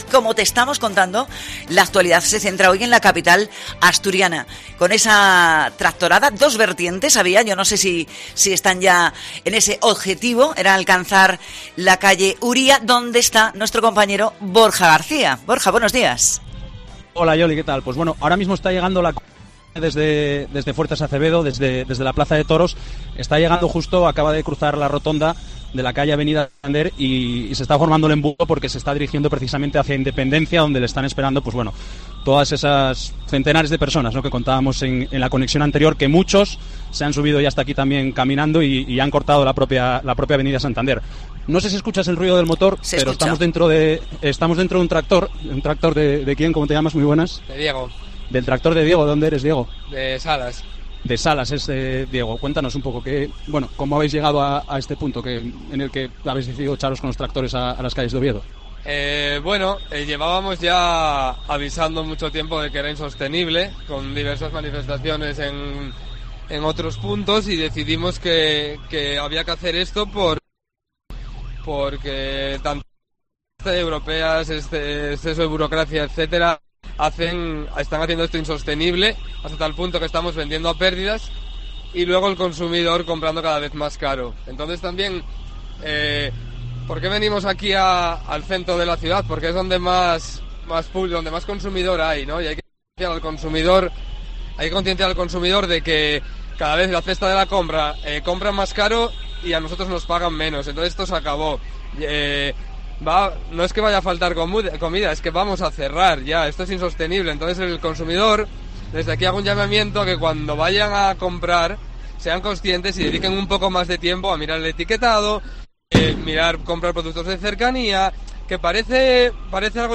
se ha subido a un tractor para contar la protesta ganadera de Oviedo